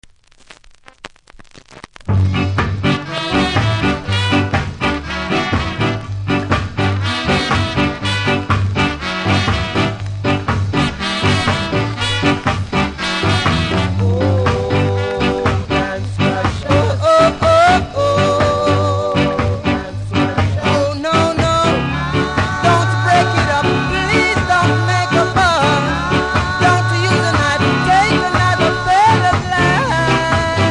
キズ多めですが音は良好なので試聴で確認下さい。